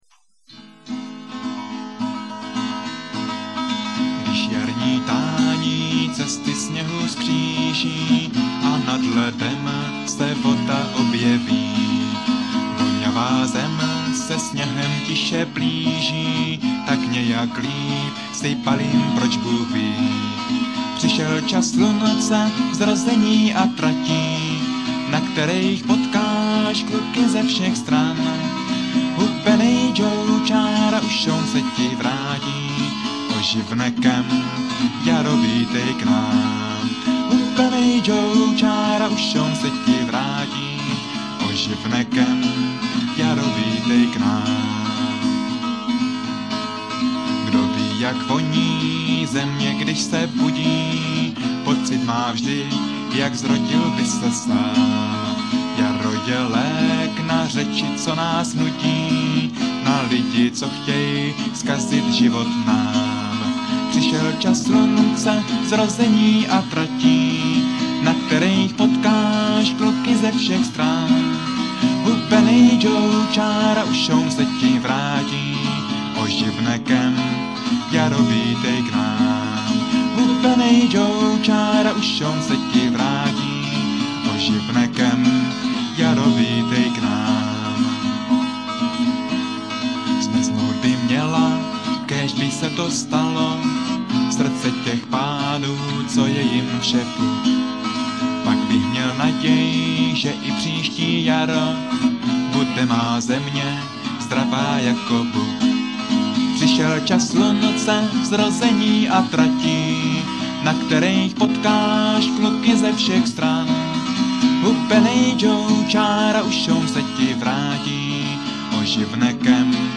zpívejte na záznamy mp3 v podání 1 kytary a dvou hlasů